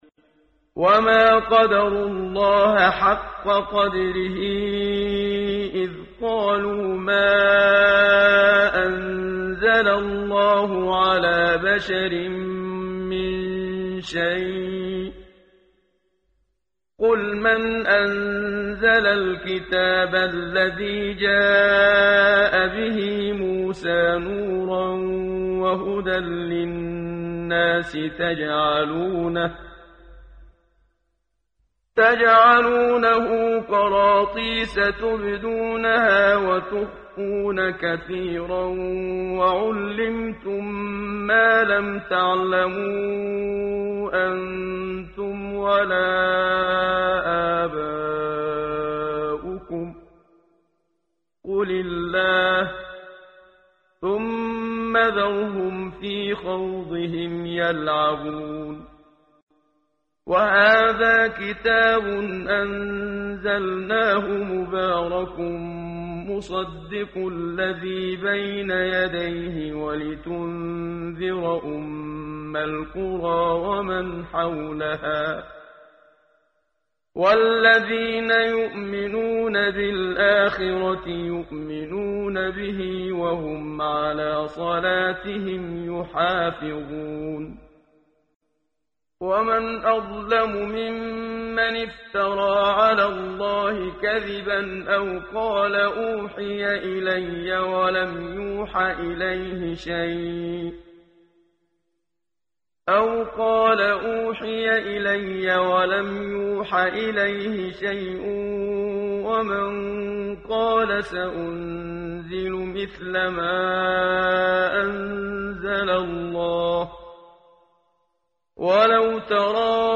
ترتیل صفحه 139 سوره مبارکه انعام (جزء هفتم) از سری مجموعه صفحه ای از نور با صدای استاد محمد صدیق منشاوی